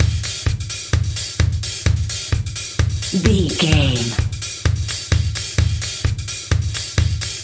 Electronic loops, drums loops, synth loops.,
Fast paced
In-crescendo
Ionian/Major
Fast
aggressive
industrial
driving
groovy
energetic
hypnotic
mechanical